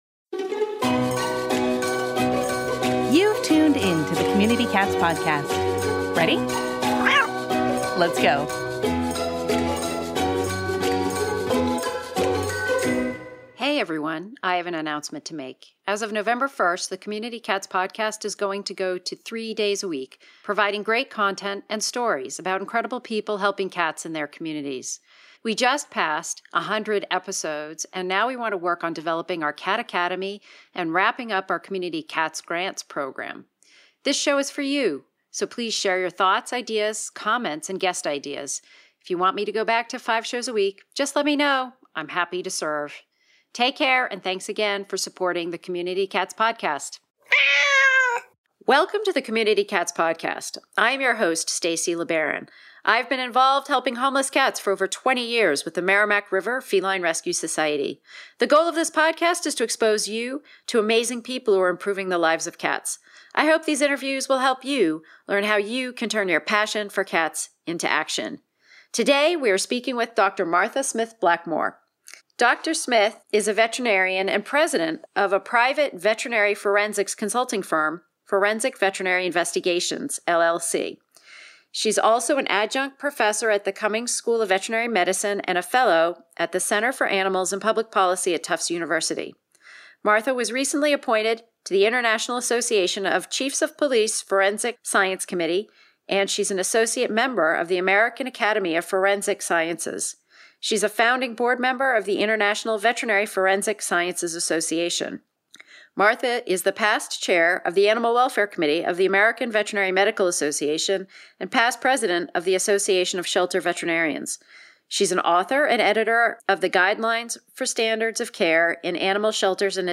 Interview!